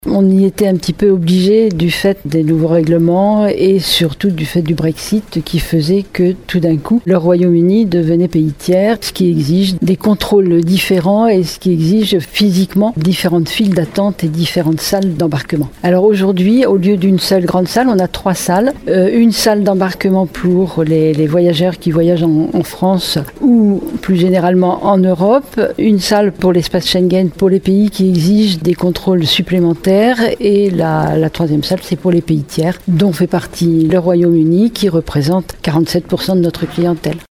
Lors de l’inauguration hier.
Des travaux à plus de 1,9 million d’euros qui étaient nécessaires, comme le souligne Catherine Desprez, première vice-présidente du Département et présidente du Syndicat mixte des aéroports de La Rochelle-île de Ré et Rochefort-Charente-Maritime :